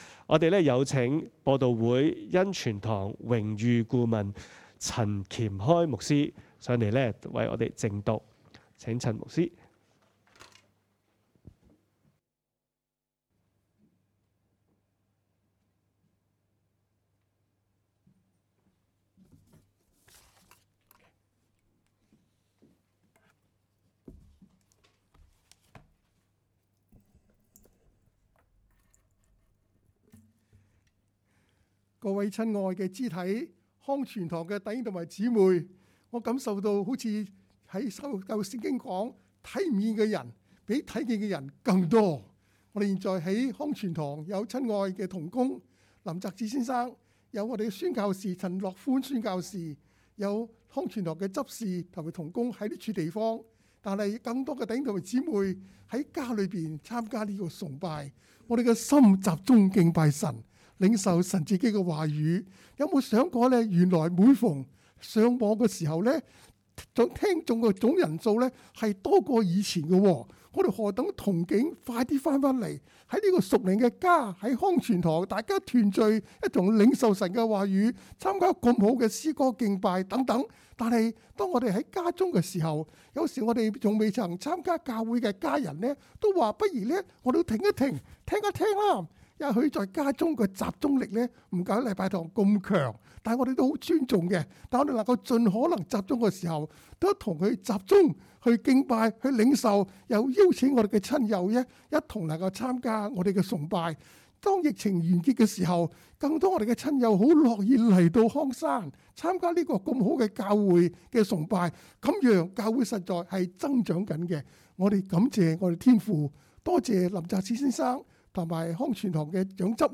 2022 年 2 月 6 日崇拜 By Church Office 23 March
講道 ：教會可以無牆嗎?